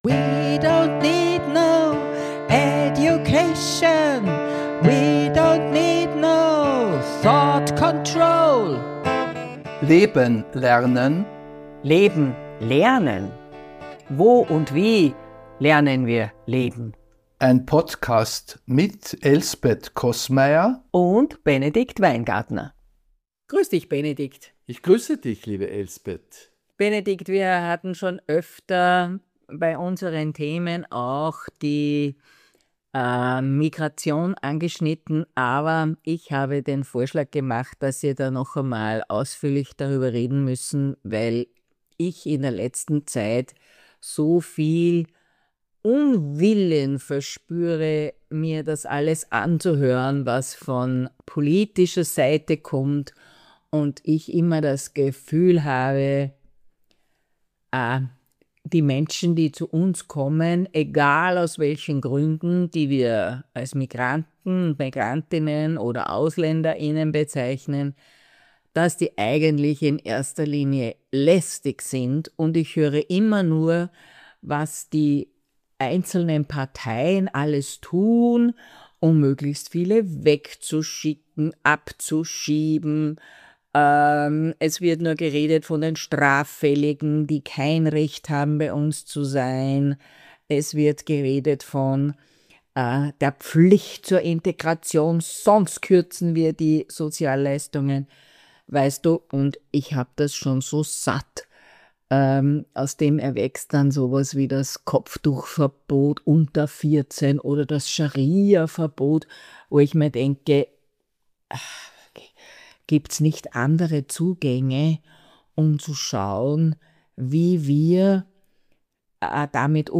Mit ihren unterschiedlichen Zugängen führen sie kritisch und mit utopischen Gedanken im Hinterkopf einen Dialog.